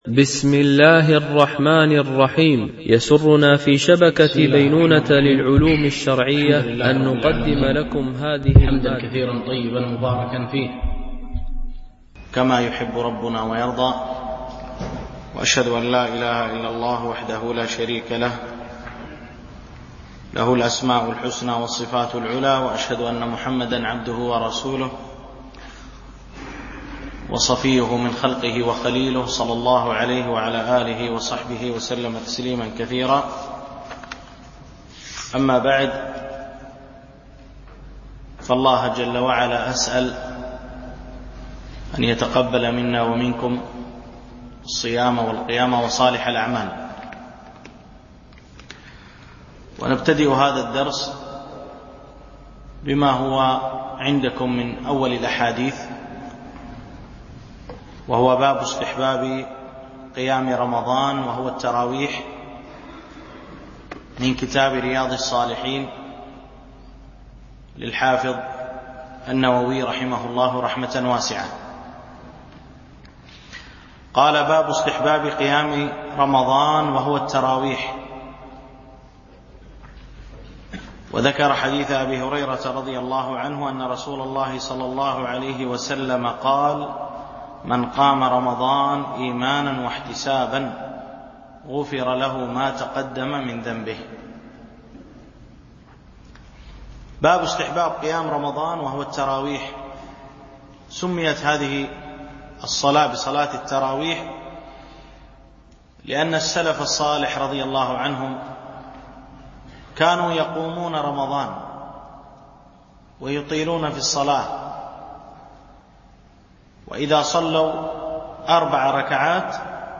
شرح أبواب الصيام من كتاب رياض الصالحين - الدرس 1